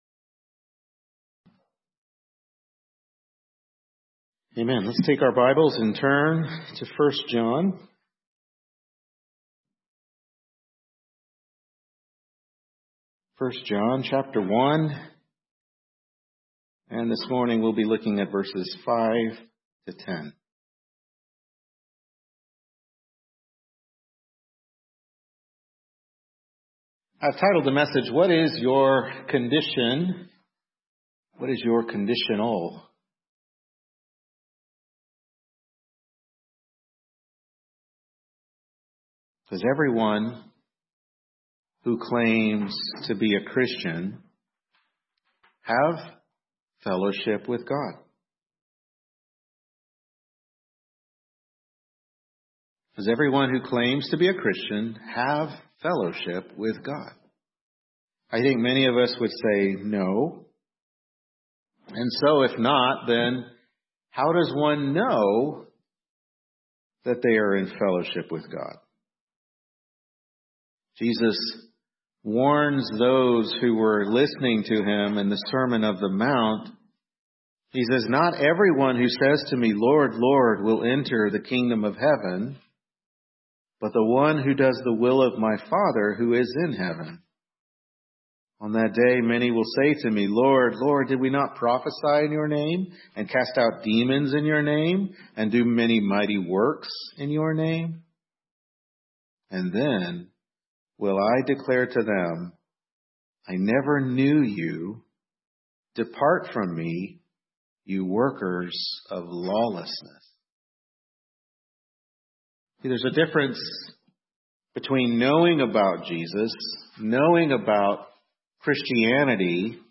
1 John 1:5-10 Service Type: Morning Worship Service 1 John 1:5-10 What Is Your Condition